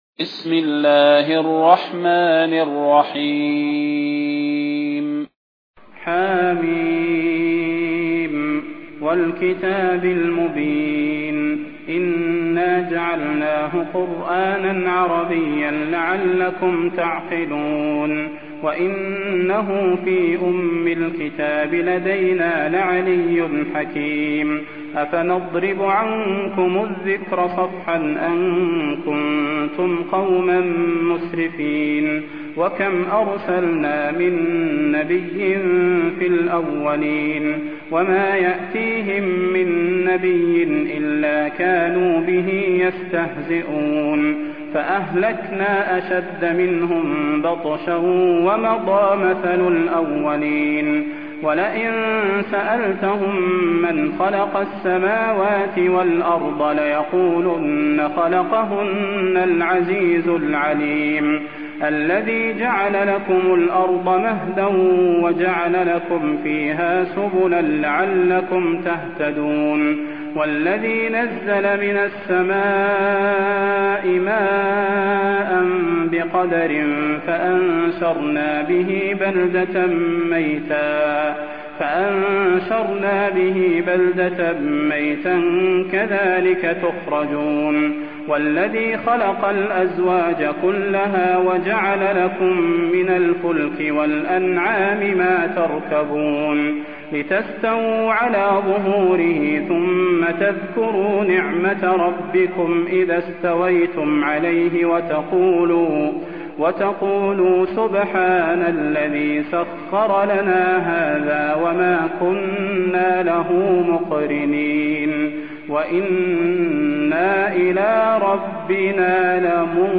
المكان: المسجد النبوي الشيخ: فضيلة الشيخ د. صلاح بن محمد البدير فضيلة الشيخ د. صلاح بن محمد البدير الزخرف